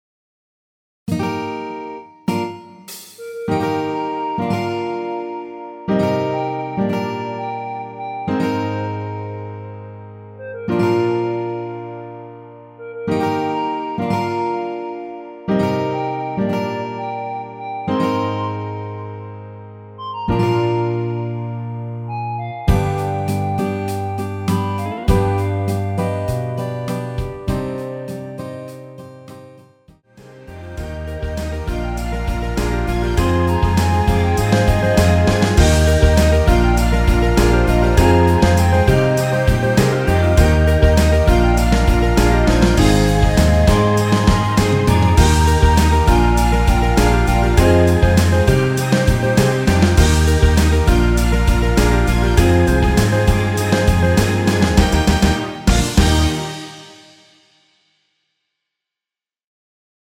원키 멜로디 포함된 MR입니다.
전주 없이 시작 하는 곡이라 1마디 드럼(하이햇) 소리 끝나고 시작 하시면 됩니다.
Eb
앞부분30초, 뒷부분30초씩 편집해서 올려 드리고 있습니다.
(멜로디 MR)은 가이드 멜로디가 포함된 MR 입니다.